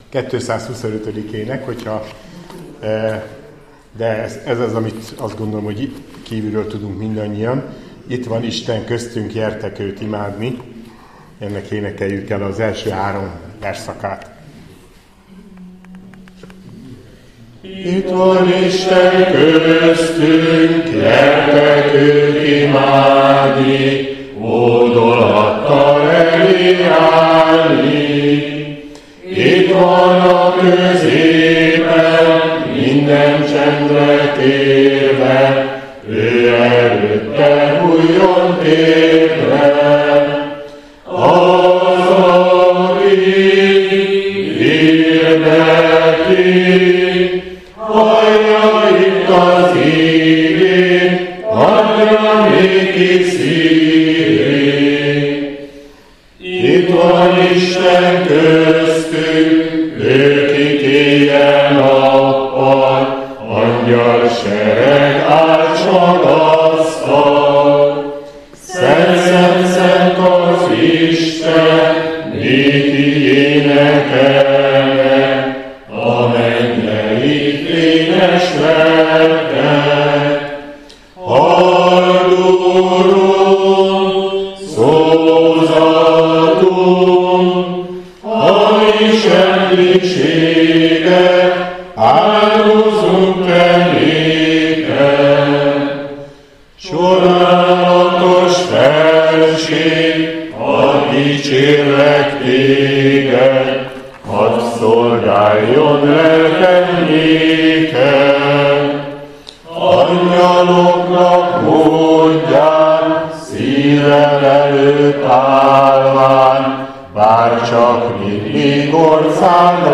A kápolnában először Balog Zoltán dunamelléki püspök szolgálatával áhítaton vettünk részt, a folytatásban pedig beszélgetés, és a fényképek vetítése következett.
A hangfelvételen meghallgatható Balog Zoltán püspök úr áhítata, az imádságokkal és a közös énekléssel együtt.